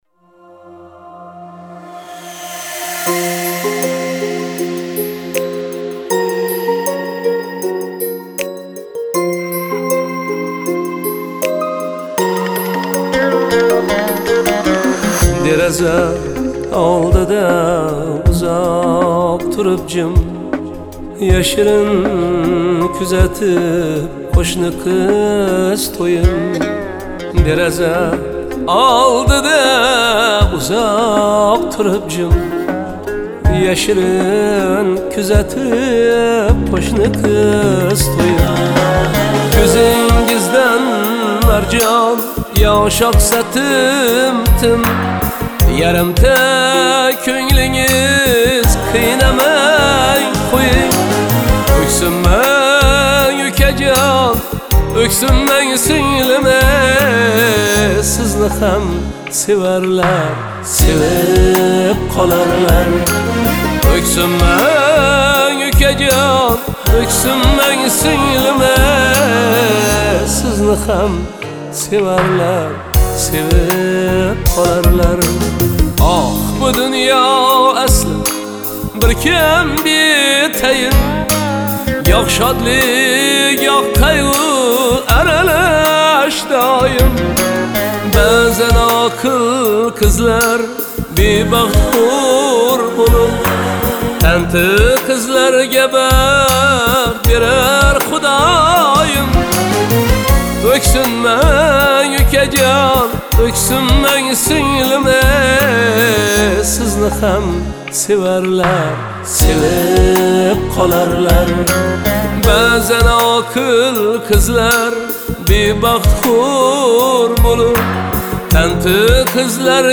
• Жанр:  Новые песни / Узбекиский новинки